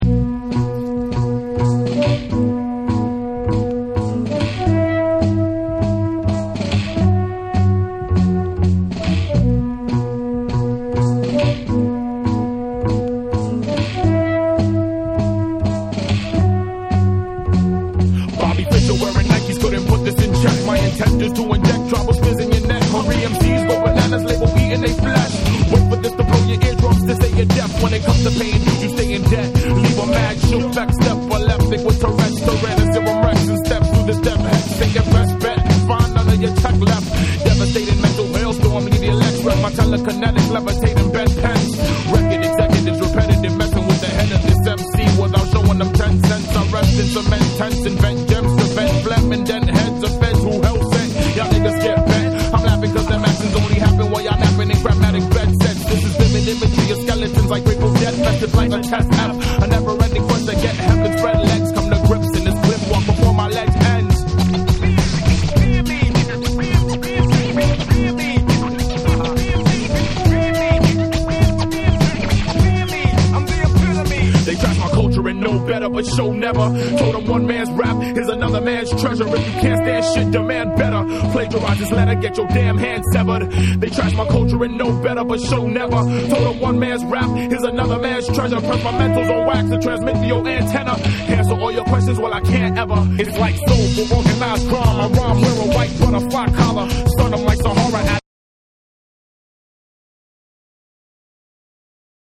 ジャズを中心としたロウな質感のサンプルとタイトなビート、トラックを引き立てるフロウが一体となり展開。
BREAKBEATS / HIP HOP